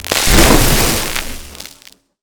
electric_surge_blast_03.wav